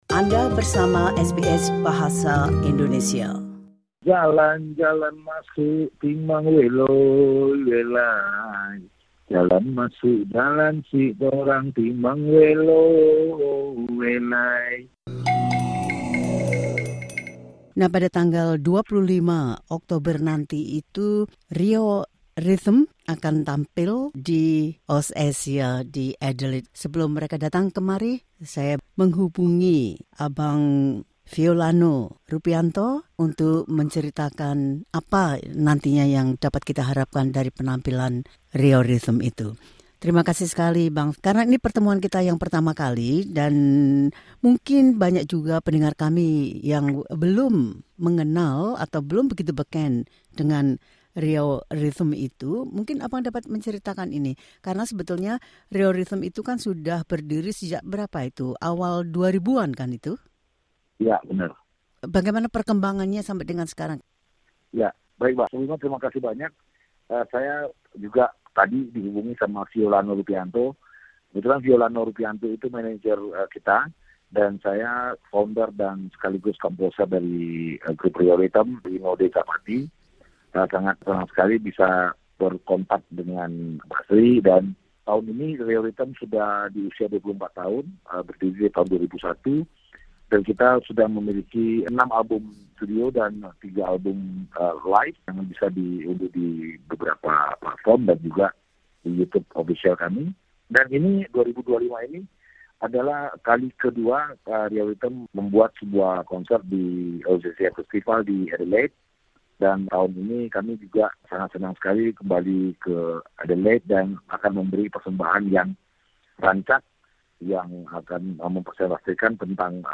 wawancaranya